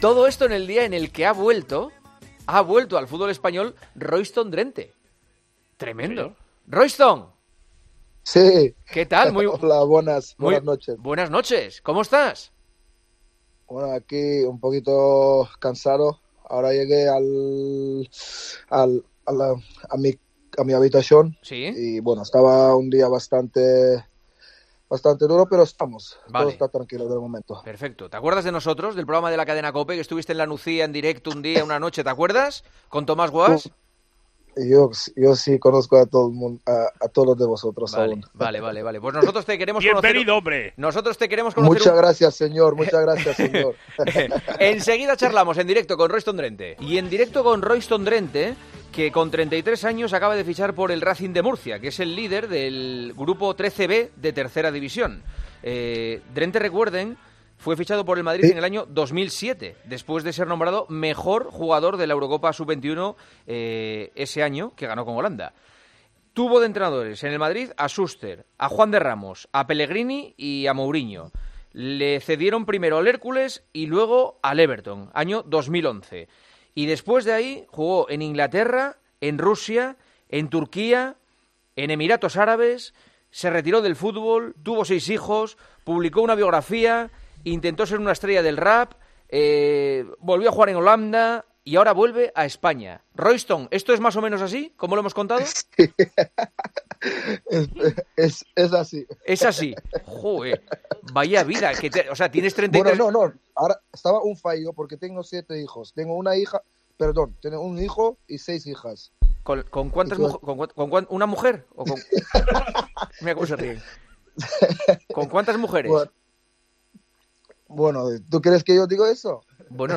El exjugador del Real Madrid ha pasado este jueves por los micrófonos de El Partidazo de COPE tras su fichaje por el Racing Murcia . Royston Drenthe, que muchos años después regresa al fútbol español se ha mostrado “ cansado ” tras “ un día bastante duro ” en el que ha comenzado a entrenarse con su nuevo equipo. Además, ha analizado la actualidad del conjunto blanco y ha recordado divertidos momentos de su pasado como futbolista en nuestro país.